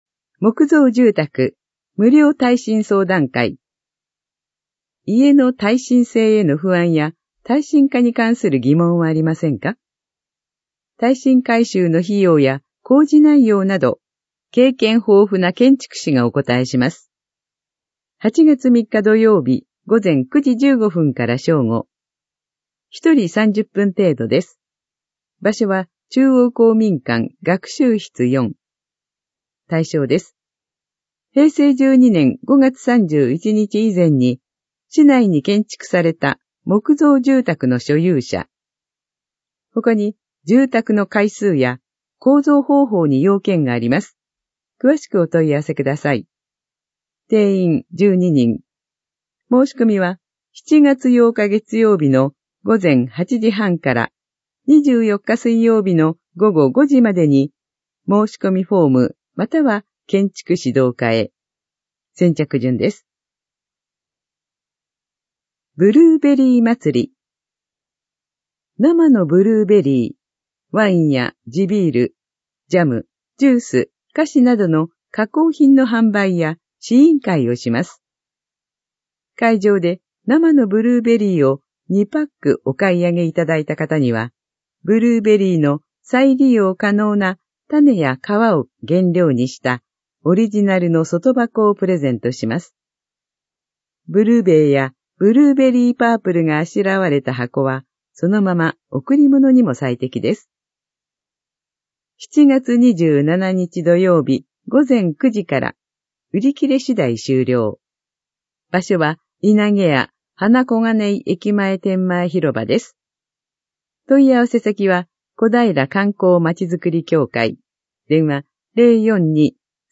市報こだいら2024年7月5日号音声版